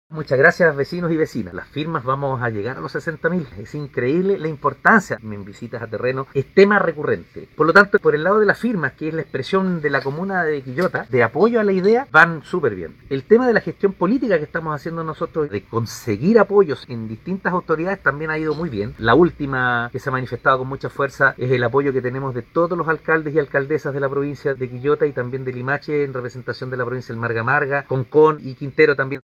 03-ALCALDE-Apoyos-conseguidos.mp3